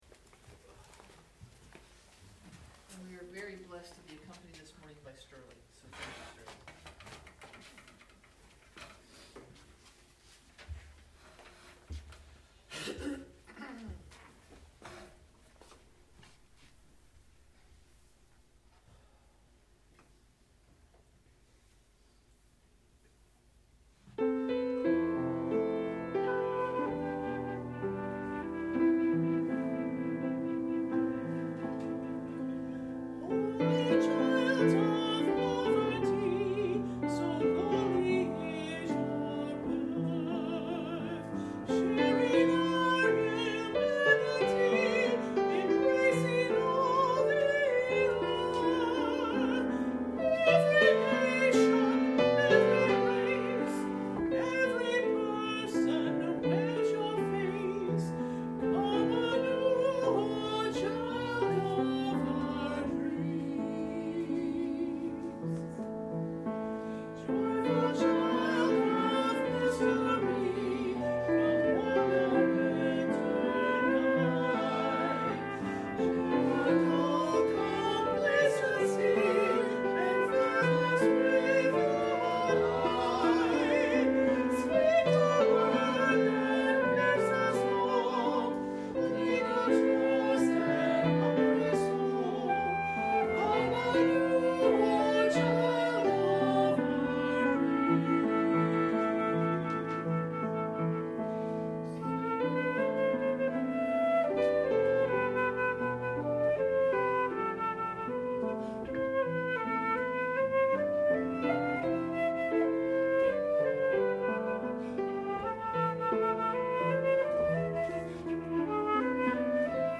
Click to listen – Child of our Dreams – with Flute – Special Music from MCC NoVA Choir
Piano
Flute